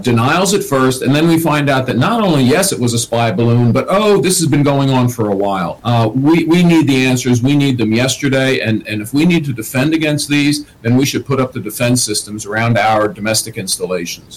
Harris spoke to Fox Business and said the government needs to respond quickly…